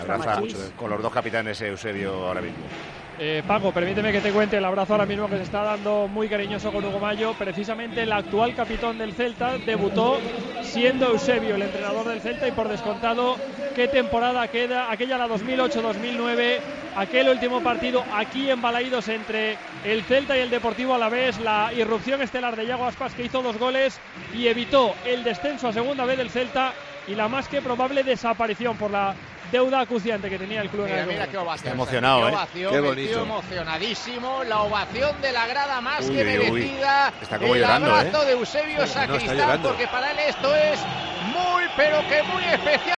El entrenador sufrió un severo accidente en 2020 del que sigue recuperándose. Realizó el saque de honor en la previa del Celta-Valladolid, donde se le vio tremendamente emocionado.